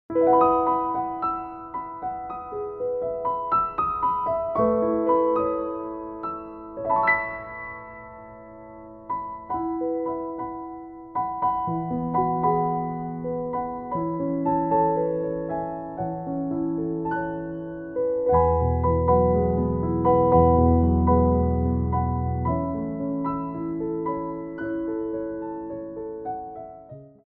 Port de Bras / Révérance
4/4 (8x8)